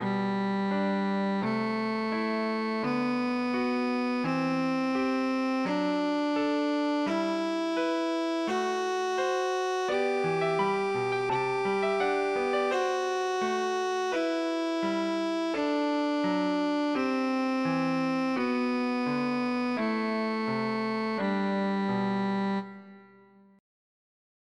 Kleines Übungsstück 3 in G-Dur für Violine
Violine mit Klavierbegleitung
Digitalpiano Casio CDP-130
Yamaha Silent Violin SV150 mit Evah Pirazzi Gold